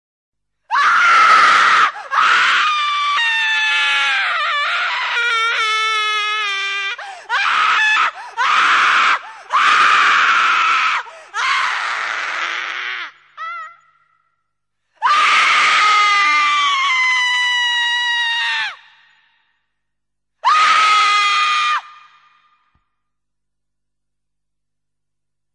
Sound Buttons: Sound Buttons View : Lady Scream
screaming-lady.mp3